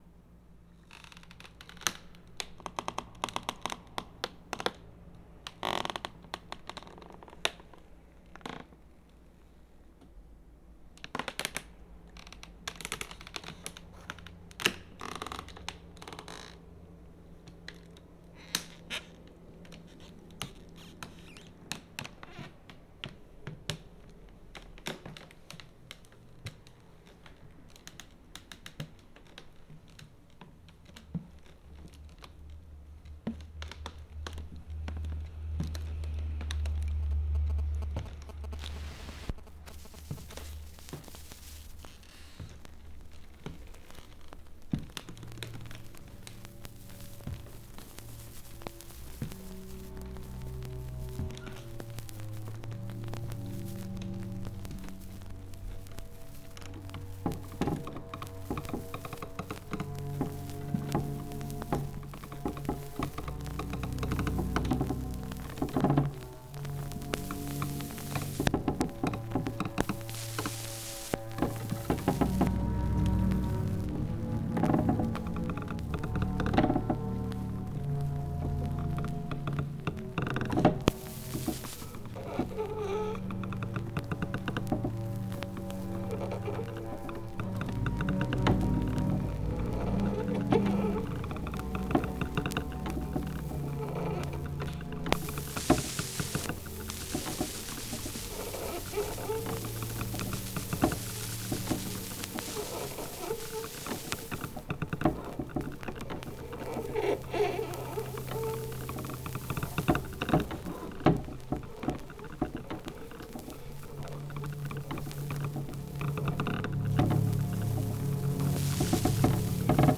Ambient Avant-Garde Electronic Experimental